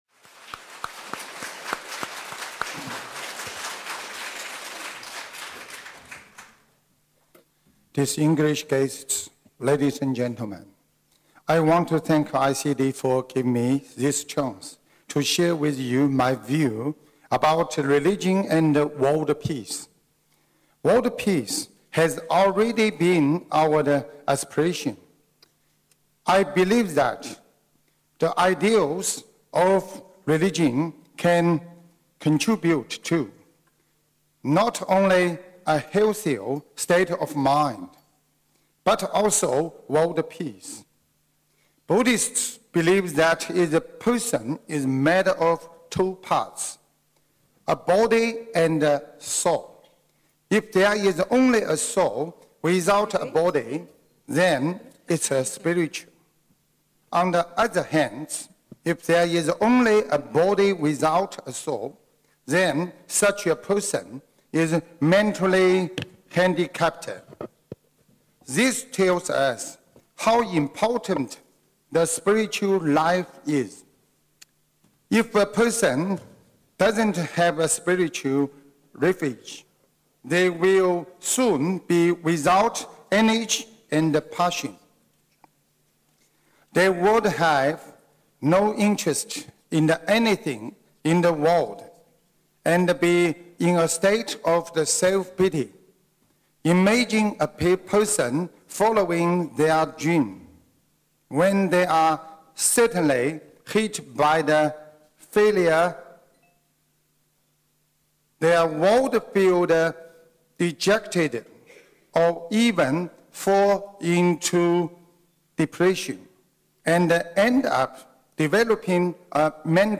意大利罗马国会 全球文化外交和宗教国际论坛